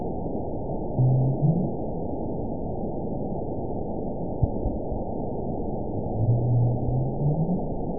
event 916294 date 12/29/22 time 09:18:04 GMT (2 years, 4 months ago) score 8.82 location INACTIVE detected by nrw target species NRW annotations +NRW Spectrogram: Frequency (kHz) vs. Time (s) audio not available .wav